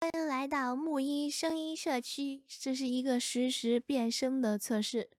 欣瑶是本站新推出的RVC全音域实时变声模型，一个支持 聊天、唱歌、咳嗽、悄悄话的女声模型，该模型的原始数据集音色介于少女和萝莉之间，这取决于使用者的个人音色。
变声：
音调：+12